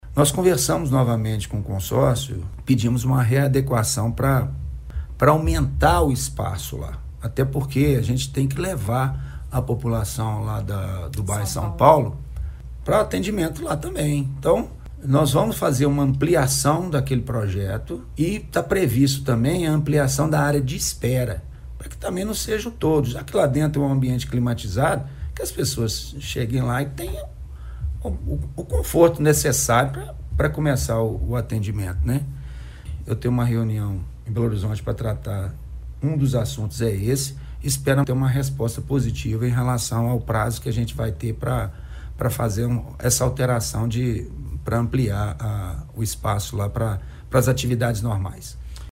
O secretário municipal de Saúde também já reivindicou a ampliação do espaço da unidade modular com contêineres para atender provisoriamente aos usuários da UBS Seringueiras, visando acolher também os moradores do Bairro São Paulo: